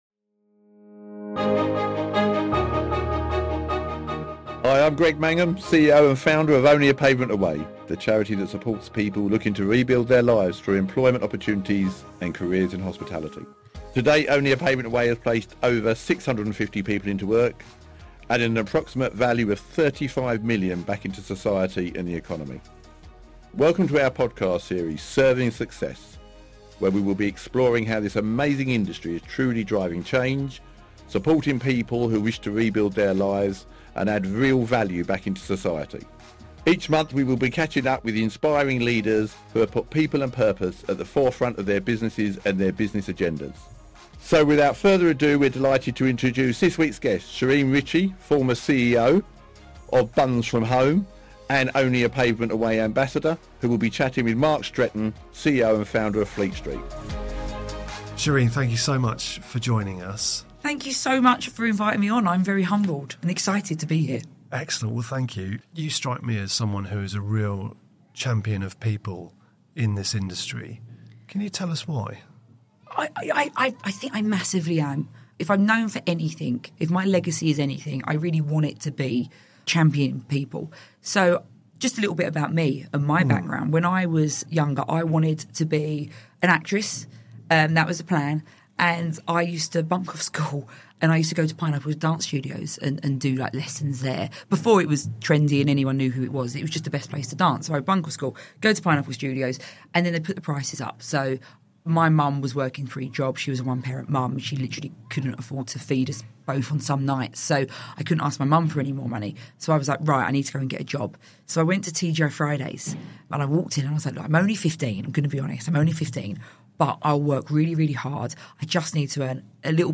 Each week, Serving Success: The Only A Pavement Away Podcast, will shine a spotlight on purpose and positive change within the industry. Our hosts will sit down with visionary industry leaders about their own journey in hospitality, how they have driven forward environmental, social, and corporate governance in their businesses, and championed diversity and inclusion.